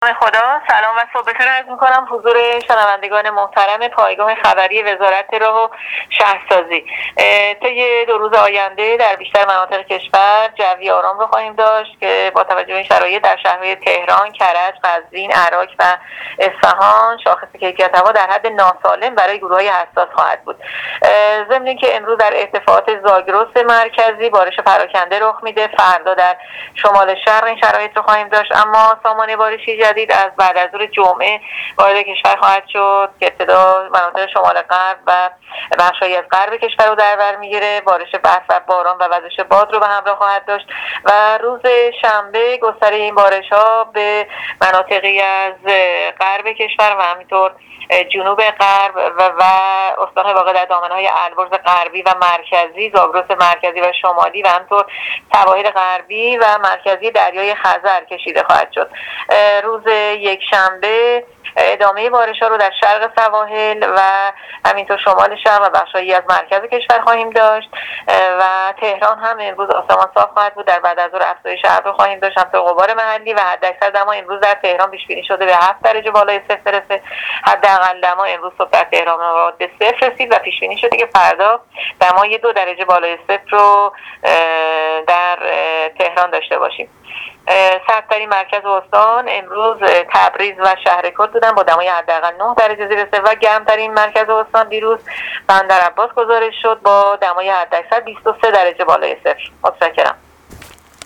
گزارش رادیو اینترنتی از آخرین وضعیت آب و هوای چهارشنبه ۹ بهمن‌ماه ۱۳۹۸